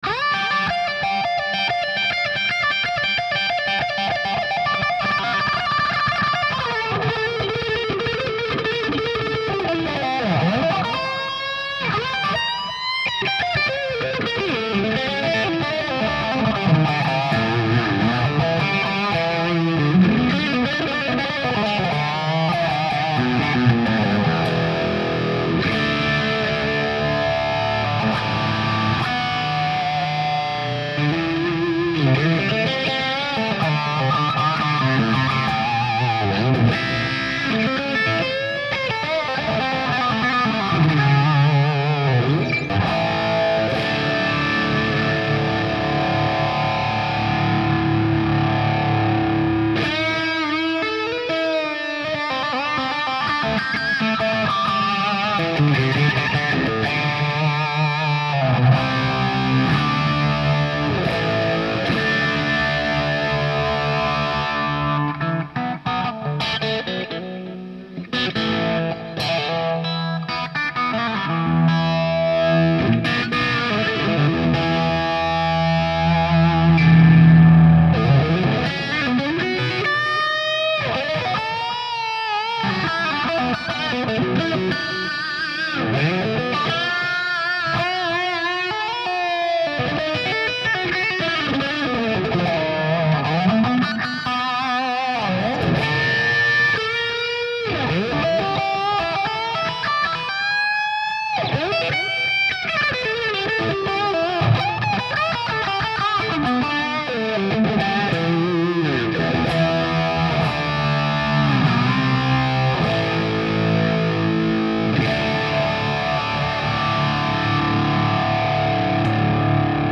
Here another demo of the XTS Atomic Overdrive. I am going for a 1976 Van Halen type tone. I used a Blankenship Variplex set clean into a Kerry Wright 4x12 with 70's Blackbacks, a Vintage Script MXR Phase 90 and an Echo Machine. The guitar is a James Tyler SE with a Seymour Duncan EVH Humbucker.
Needs maybe a smidge more prescence bite for me but I bet that could be adjusted in easy.
Sounds pretty high gain with a high smash factor, it you will.